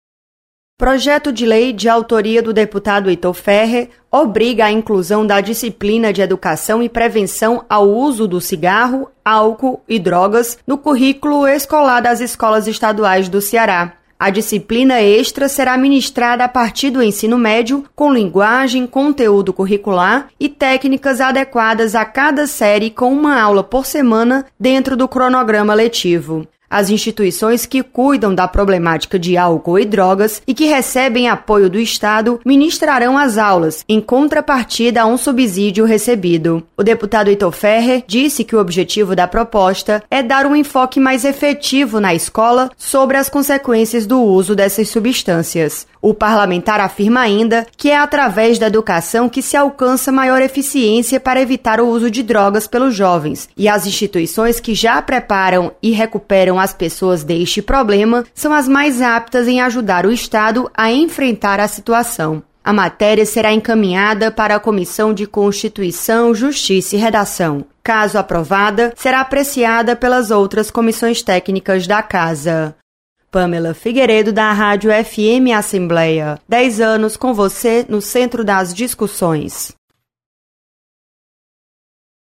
Deputado sugere inclusão de disciplina sobre prevenção de drogas no currículo escolar. Repórter